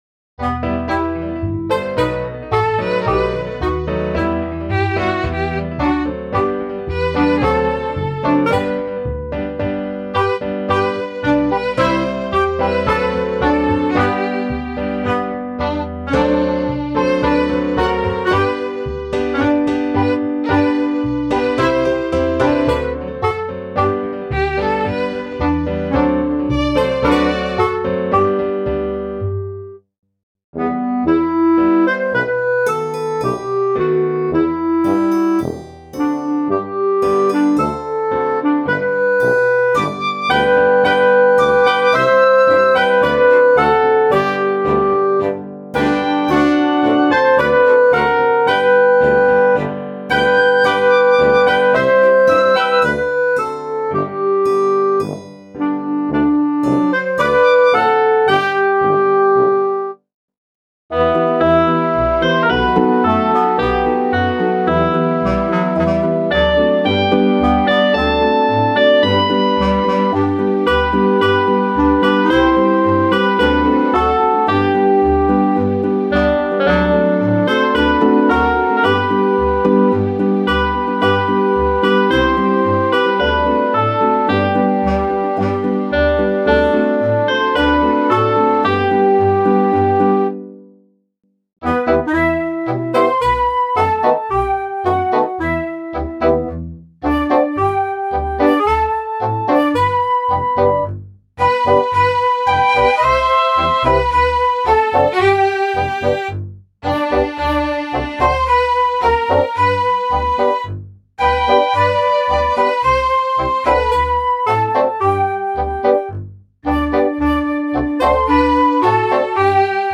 Instrumentalsätze